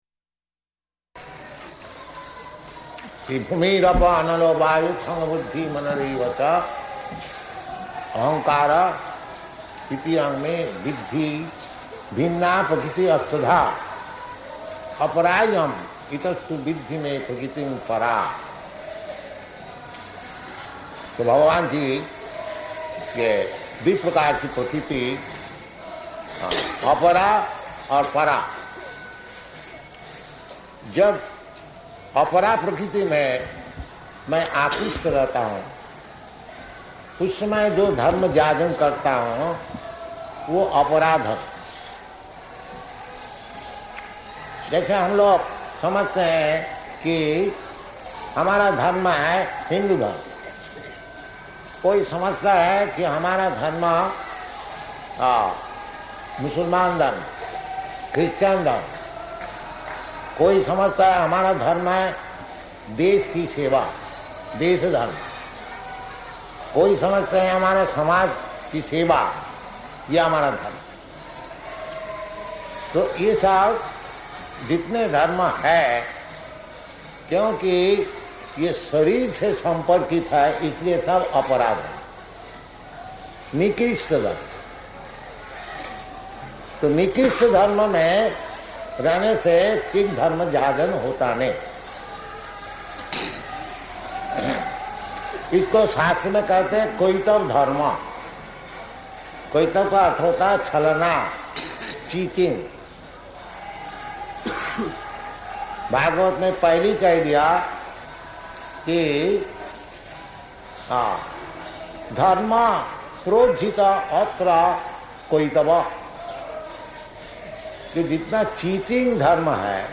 Lecture in Hindi
Type: Lectures and Addresses
Location: Jaipur